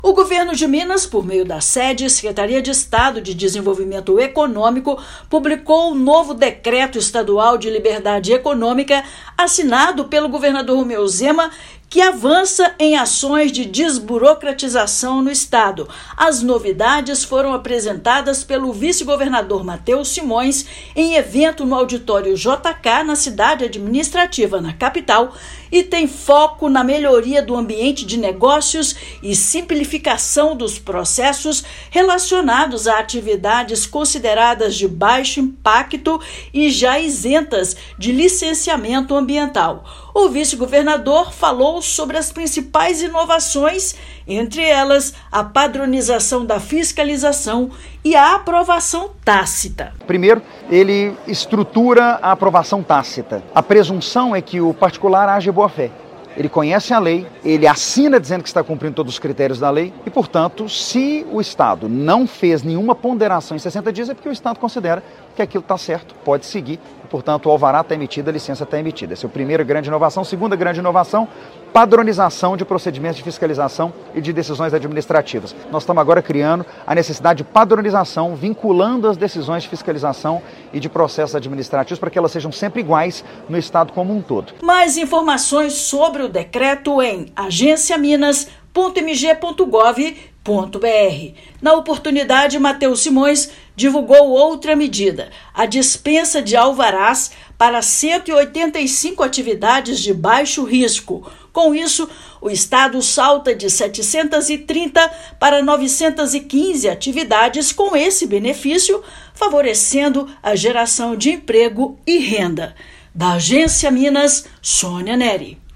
Vice-governador apresenta medidas que vão trazer mais previsibilidade e segurança para quem quer empreender e gerar empregos. Ouça matéria de rádio.